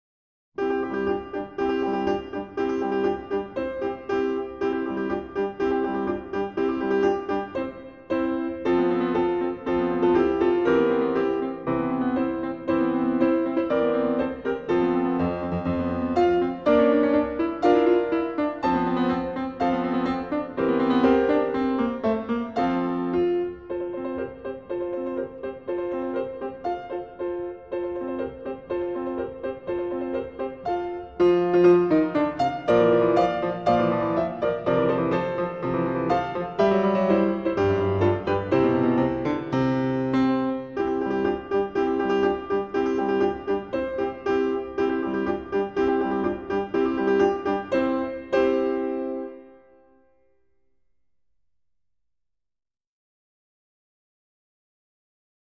0193-钢琴名曲鼓手.mp3